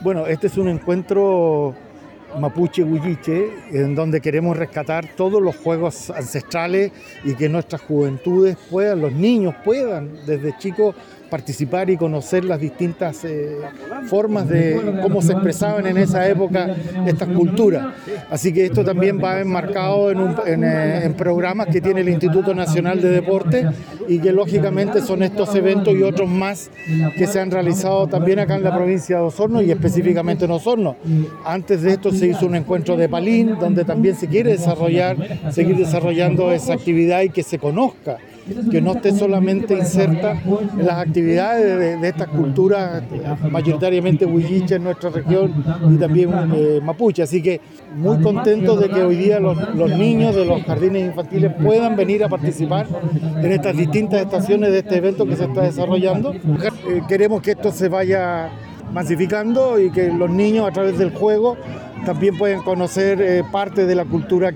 En tanto el Director Regional del IND, Ernesto Villarroel, señaló que este encuentro tiene suma importancia para que los niños puedan aprender acerca de la cultura mapuche huilliche.